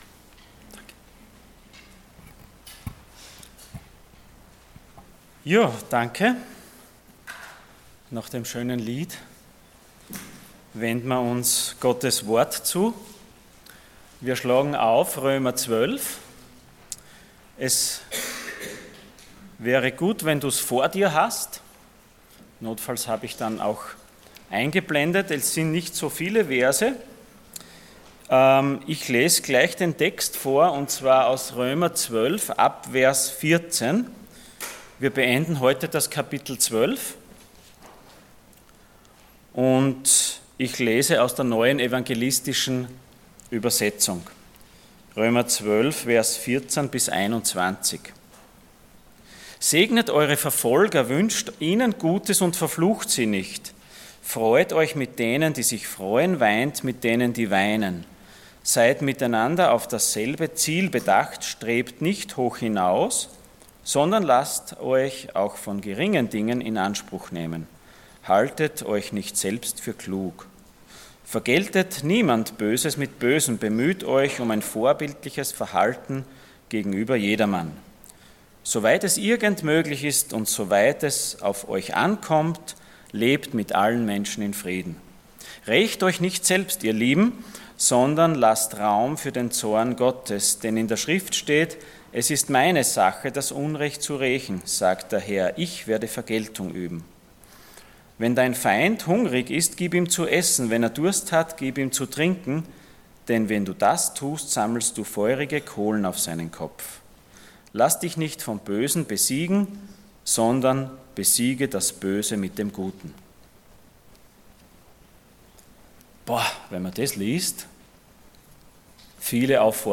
Passage: Römer 12,14-21 Dienstart: Sonntag Morgen